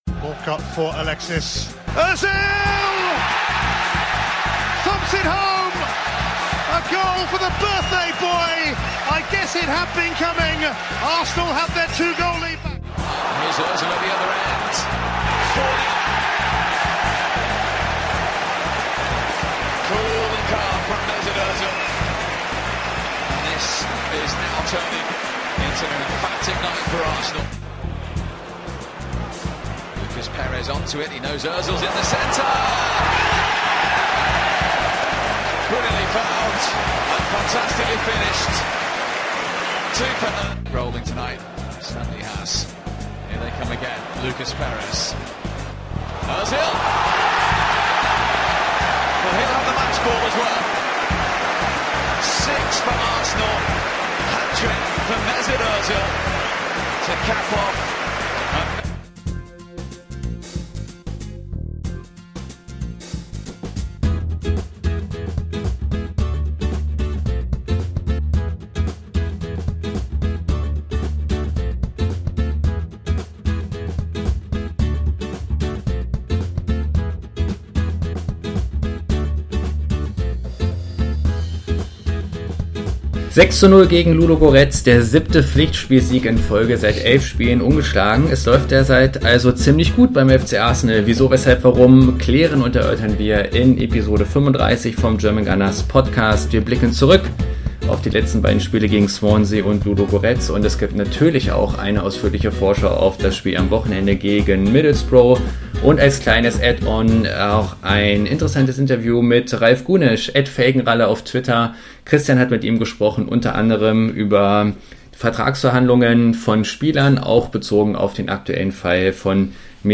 Nach der gestrigen Gala gibt es heute natürlich viel zu besprechen und vor allem zu würdigen. Dazu haben wir uns natürlich wieder einen namhaften Gast in den Podcast eingeladen.